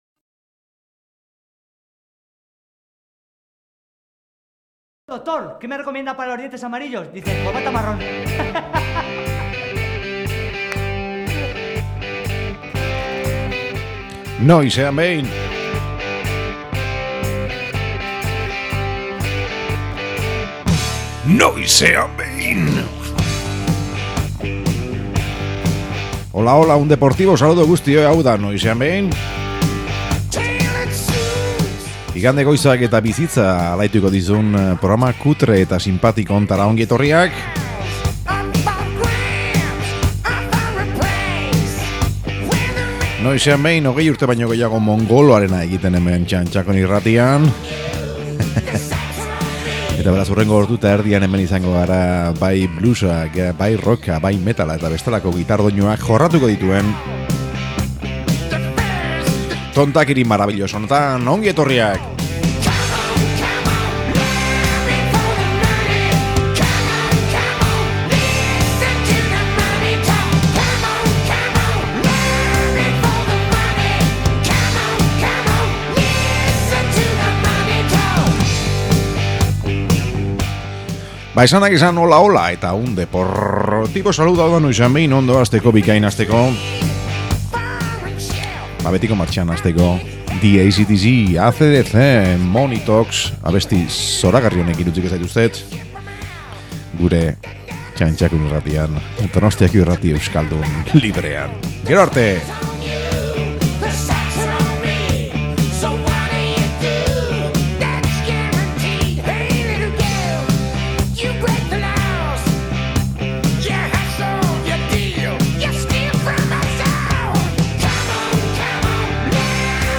Beste behin ere programa gozo gozoa, blues, rock, heavy hirukoan.